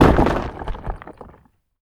rock_smashable_hit_impact_03.wav